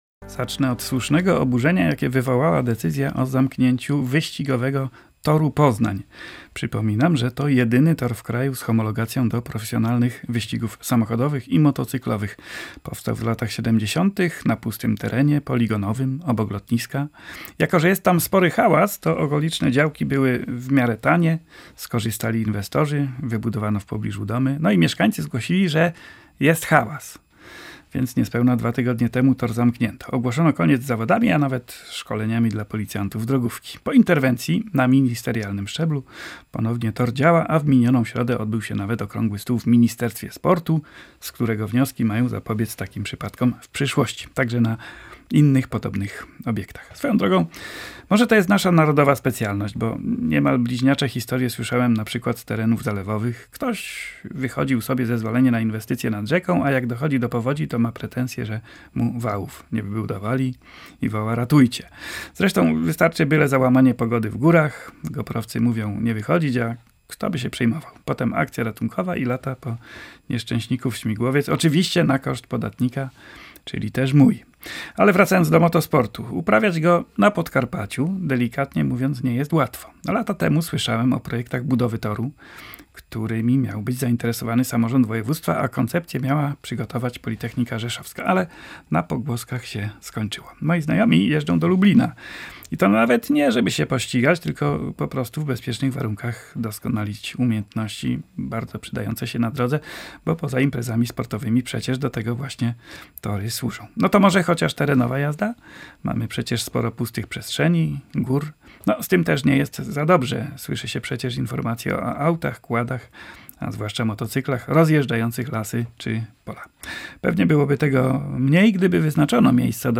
Powitamy gościa, który opowie o akcji zbierania historycznych fotografii związanych z motoryzacją